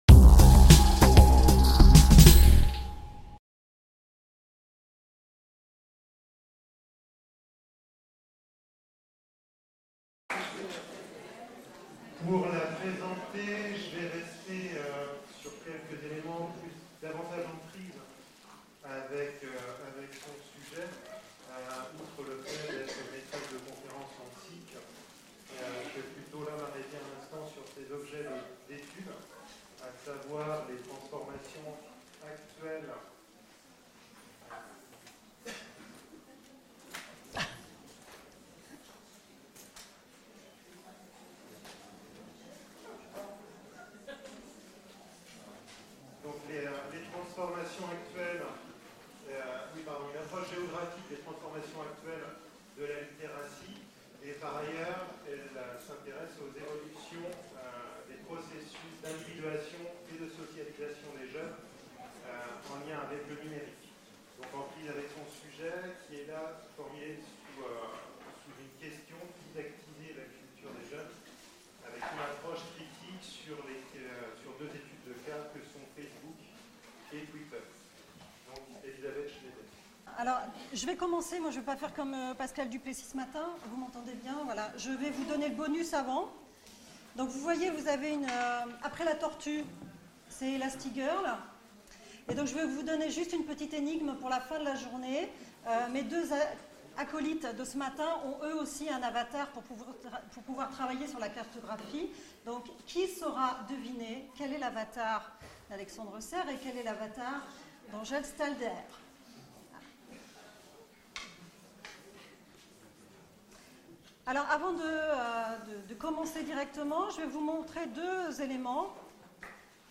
Cette conférence se propose d'examiner quelques dimensions à prendre en compte dans un processus didactique et pédagogique si l'on veut former les élèves à un usage critique des réseaux communautaires.